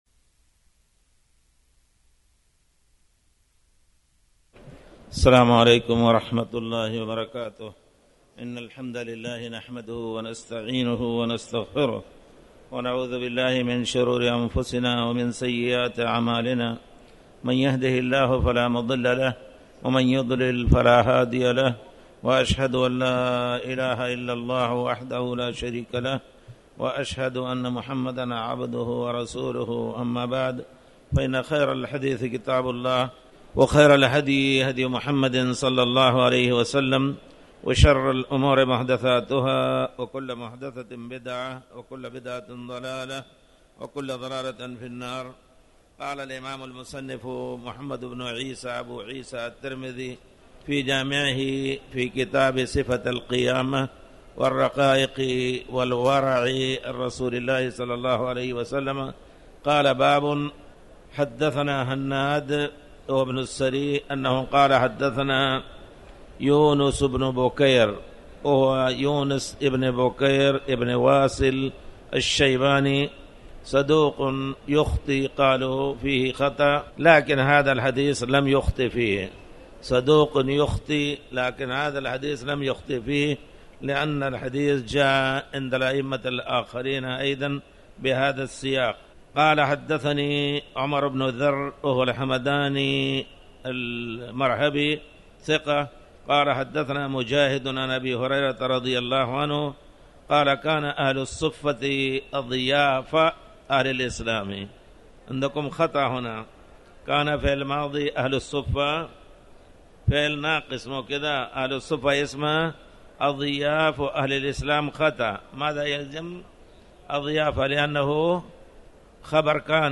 تاريخ النشر ١٩ جمادى الآخرة ١٤٣٩ هـ المكان: المسجد الحرام الشيخ